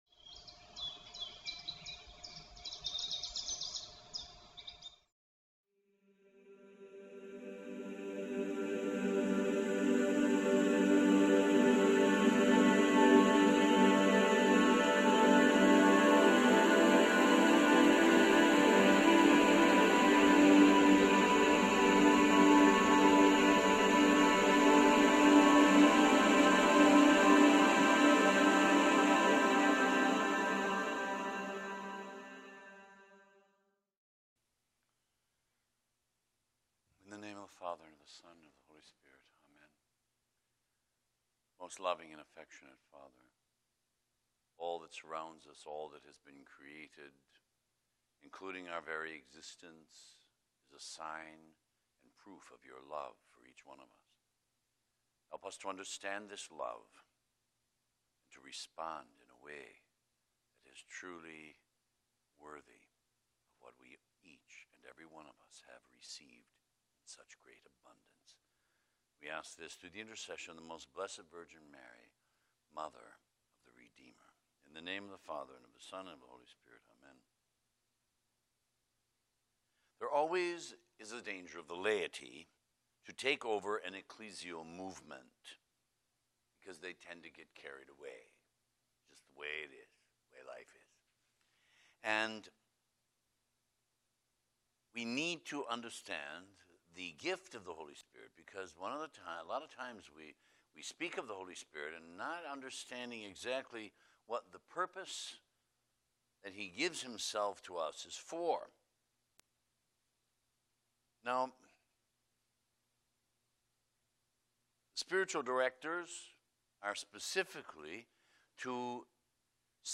gives the sixth of twenty-five conferences for his Eight Day Ignatian Retreat. The subjects he covers are: Indwelling of the Holy Trinity. What is the kingdom of Christ?